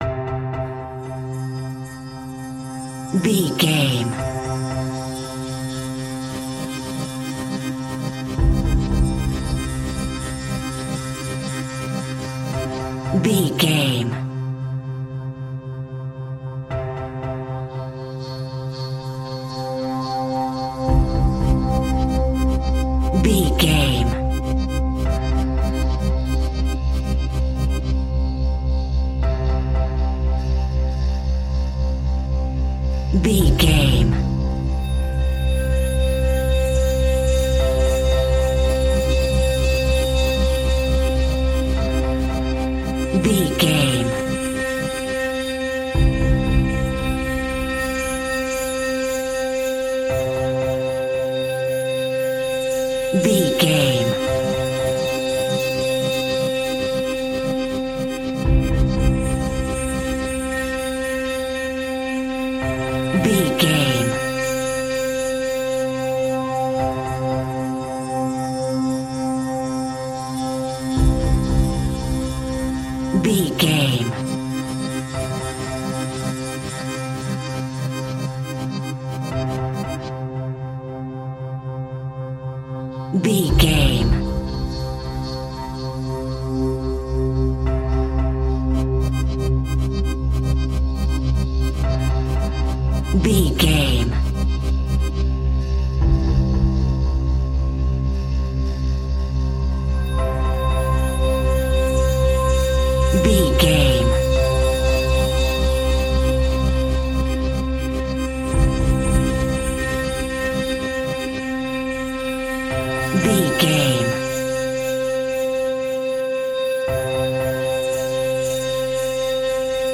Ionian/Major
ethereal
dreamy
tension
suspense
synthesiser
accordion
electronic
drone
synth drums
synth bass